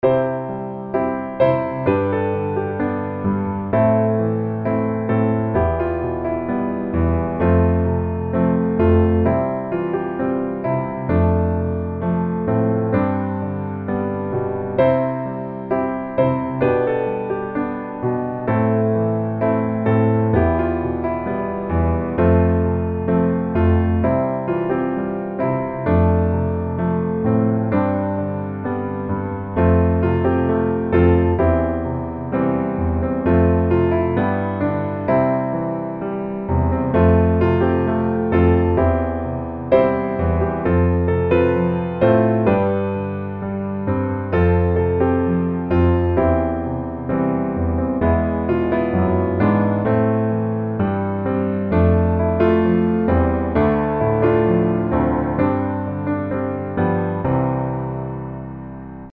C Majeur